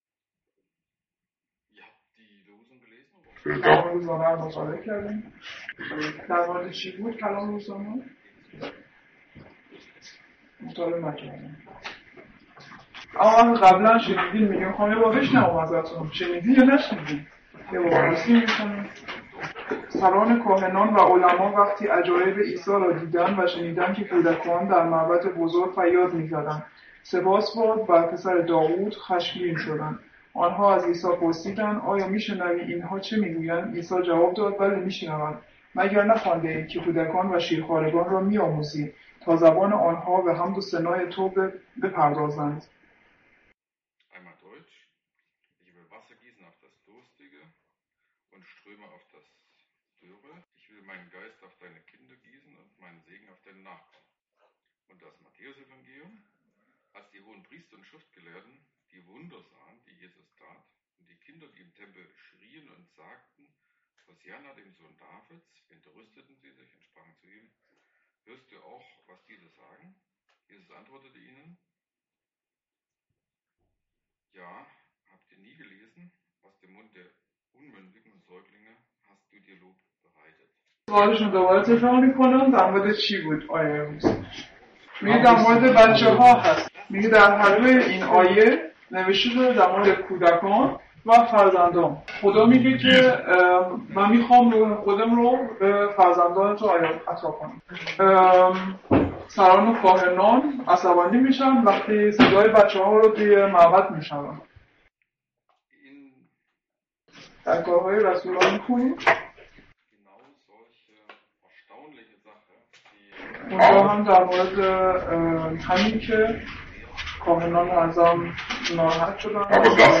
Übersetzung auf persisch.